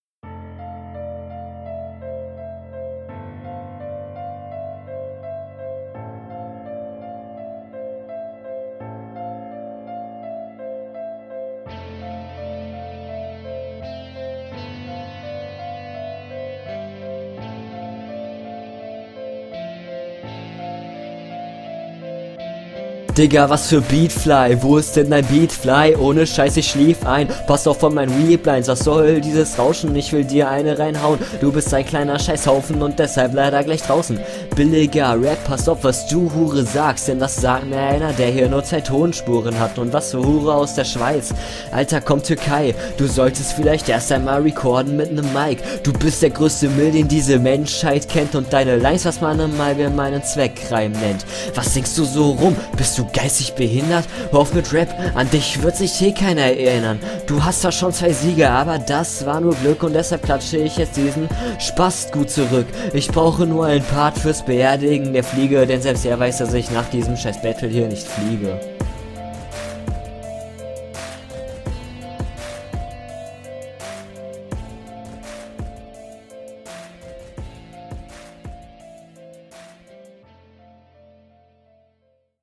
also man versteht dich, jetzt zu deinen Parts bzw deinem Rap.
offensichtlicher Spit, welcher aber sehr gut umgesetzt wurde.